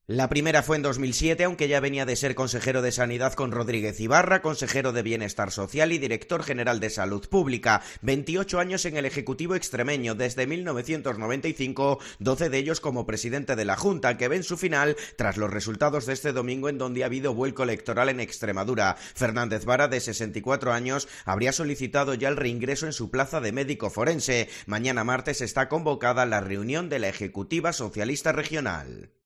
da más detalles sobre la marcha de Fernández Vara